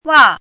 怎么读
[ wǎ ]
wa4.mp3